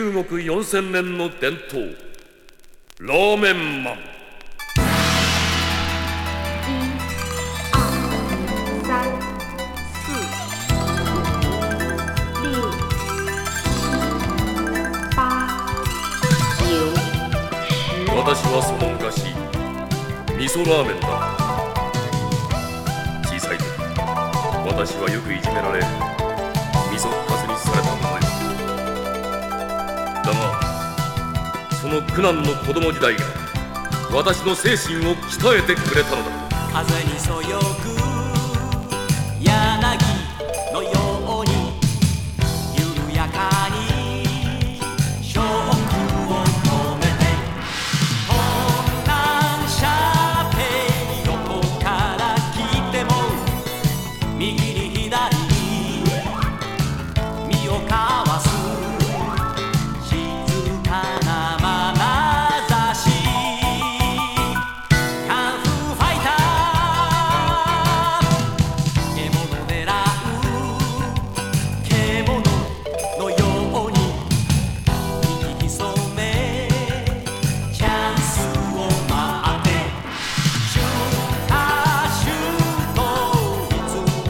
※盤スリキズ、キズ有。